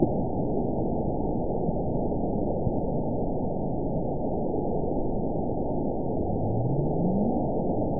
event 922185 date 12/28/24 time 00:07:08 GMT (11 months, 1 week ago) score 9.49 location TSS-AB04 detected by nrw target species NRW annotations +NRW Spectrogram: Frequency (kHz) vs. Time (s) audio not available .wav